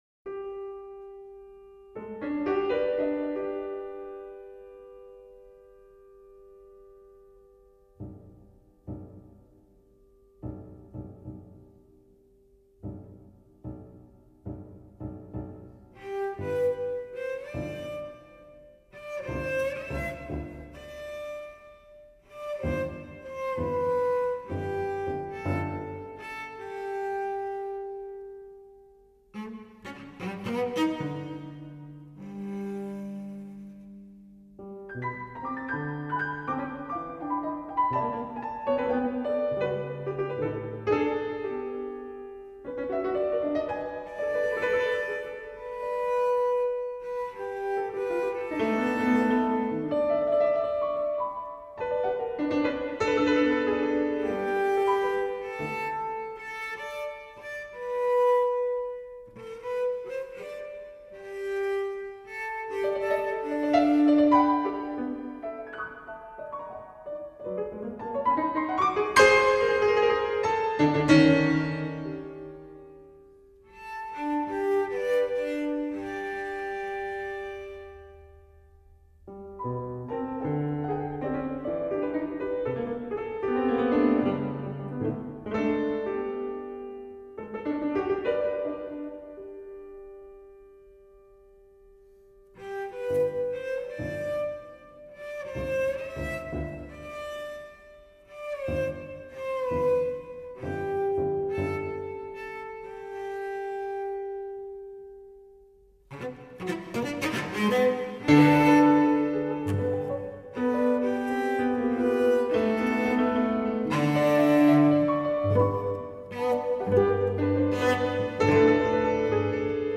conversazioni